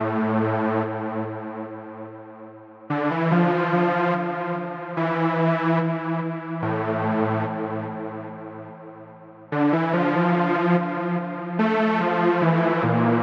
描述：在Fl studio 12中用sylenth制作与"hard loop 1"中使用的声音相同，一起用力。
Tag: 145 bpm Trap Loops Synth Loops 2.23 MB wav Key : A